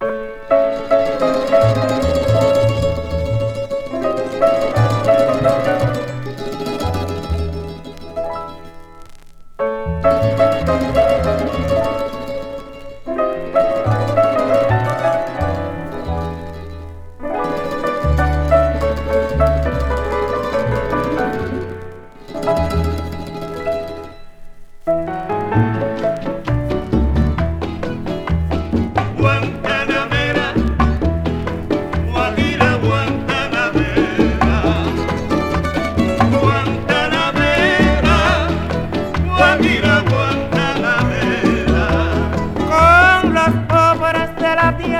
World, Latin, Son　Venezuela　12inchレコード　33rpm　Mono